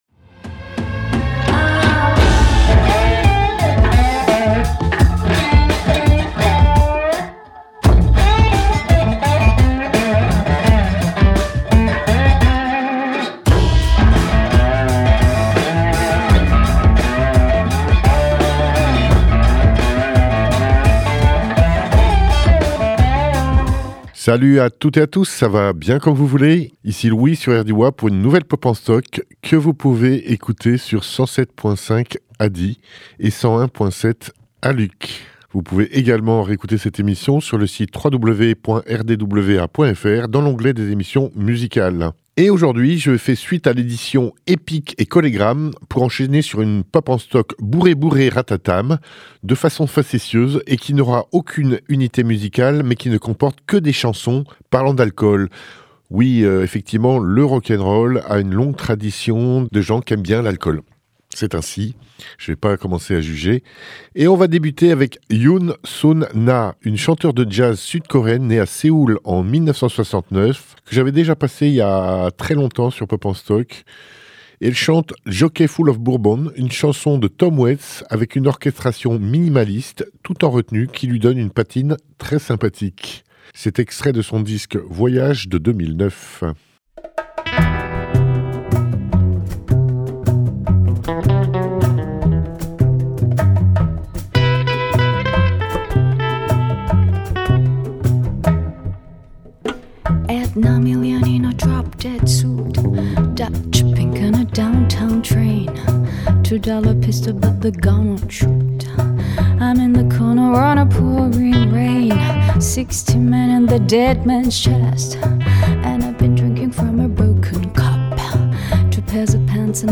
folk , jazz , rock